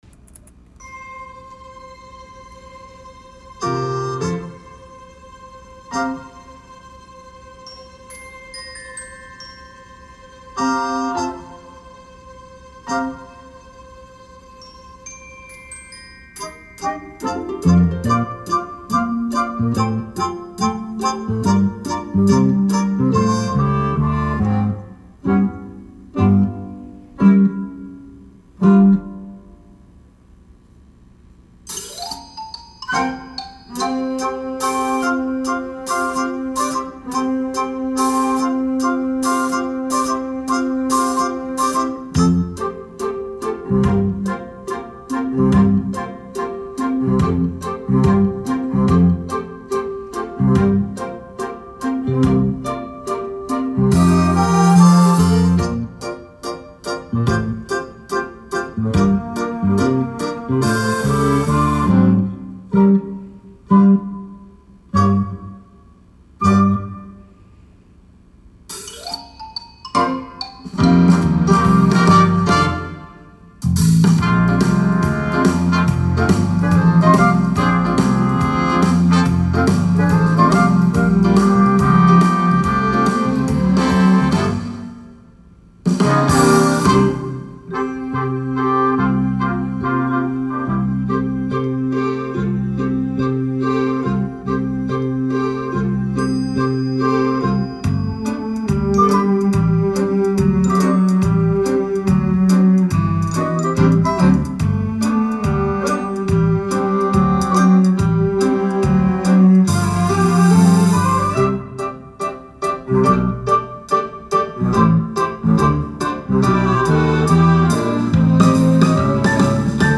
The Story of Buddy Instrumental